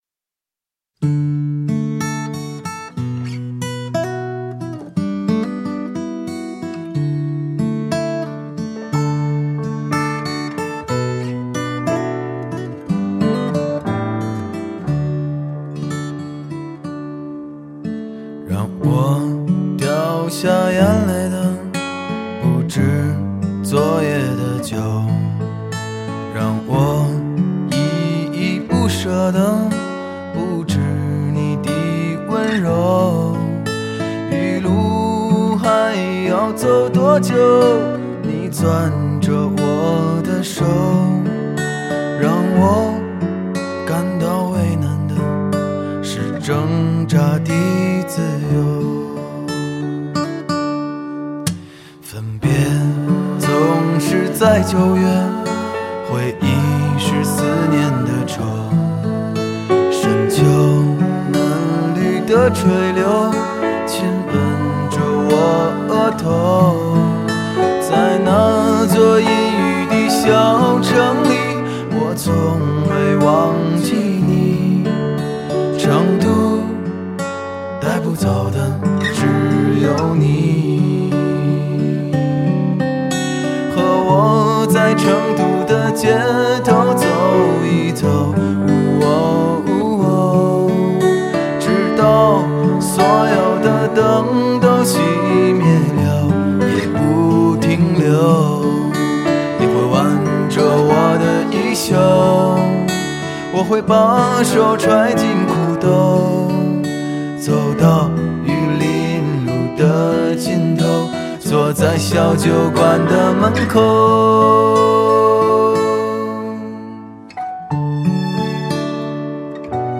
Китайская музыка